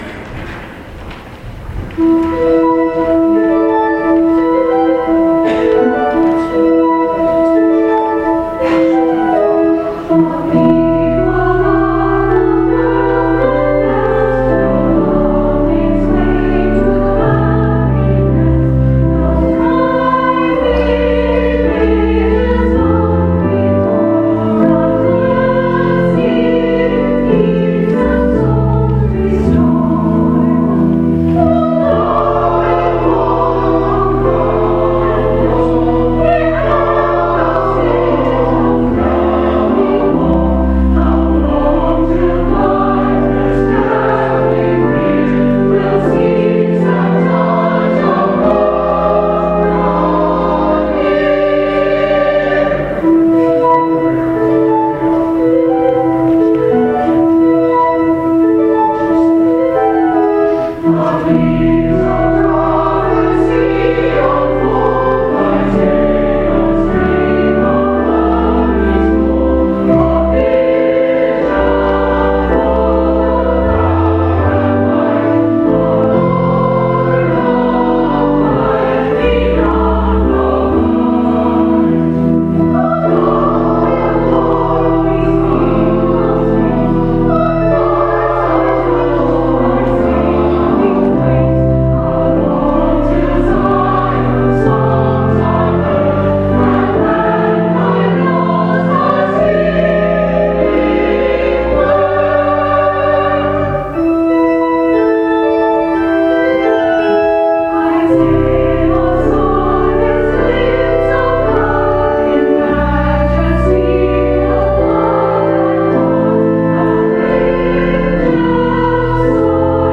MCC Senior Choir Offertory November 29, 2015